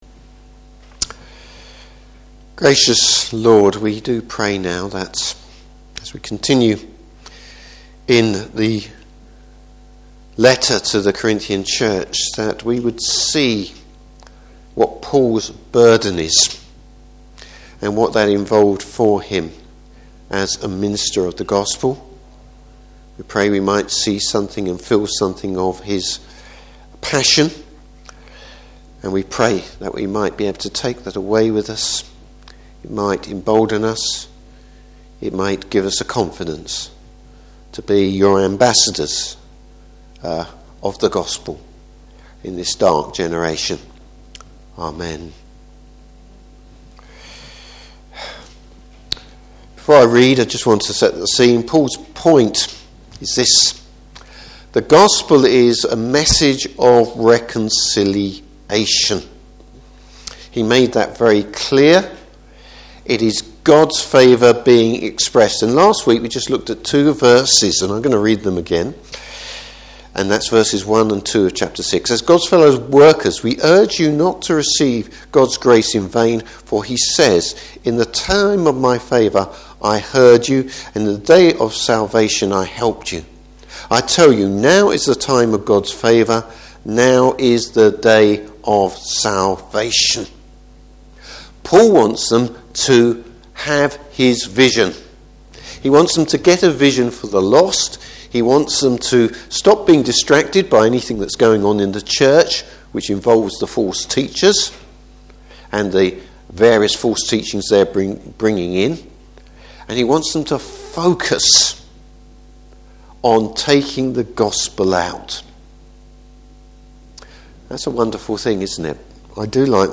Service Type: Morning Service The importance of the Gospel.